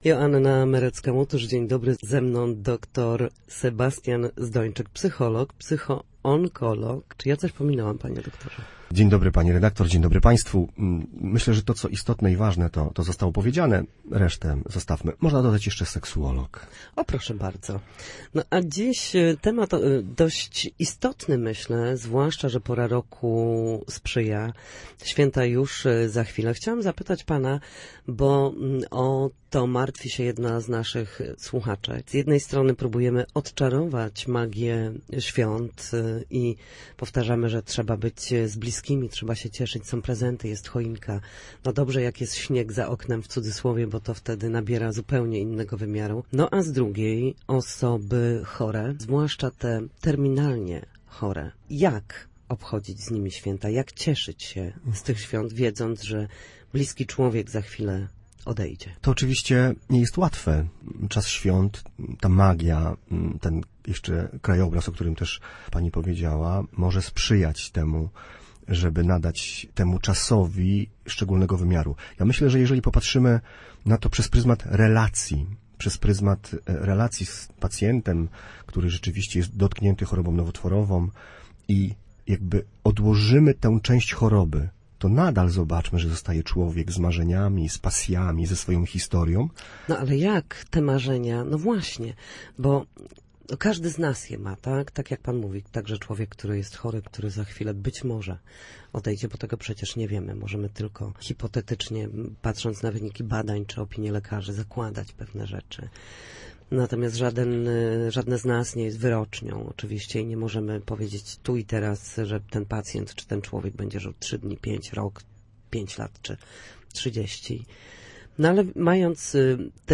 Na antenie Radia Gdańsk mówił o świętach z osobą nieuleczalnie chorą.